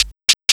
PERC LOOP3-L.wav